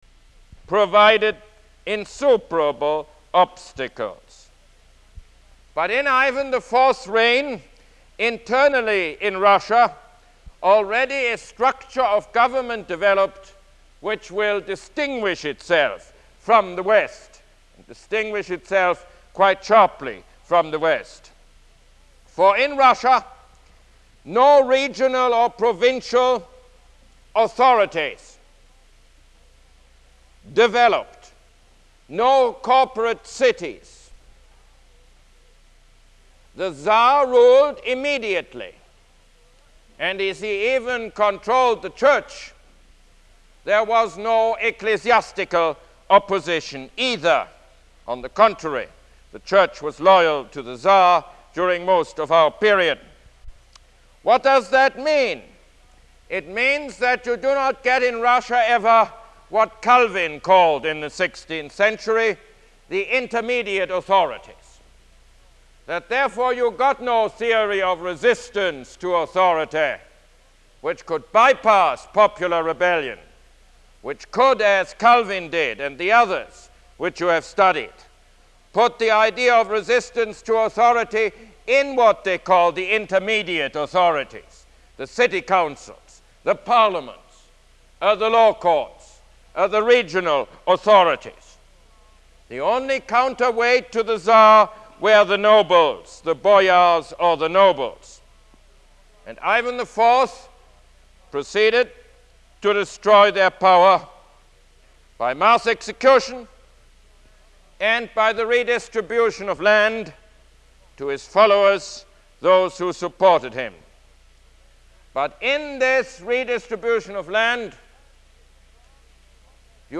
Lecture #18 - The Rise of Prussia